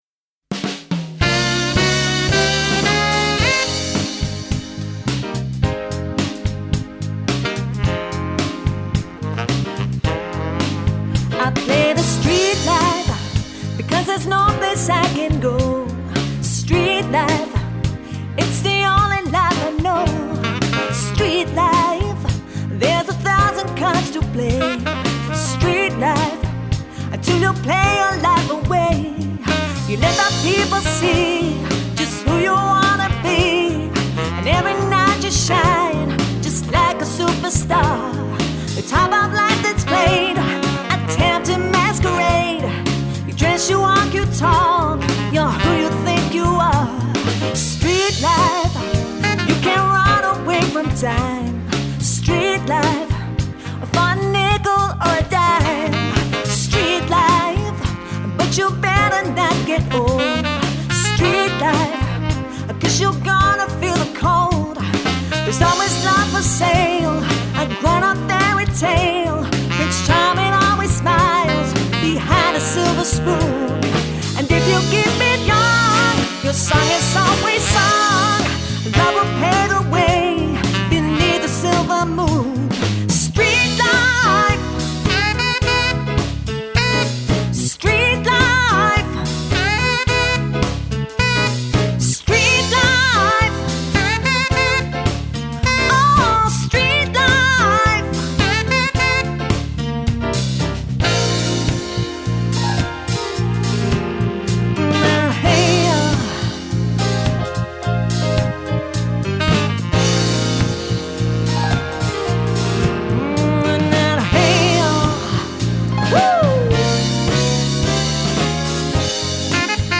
vocals
Sax
drums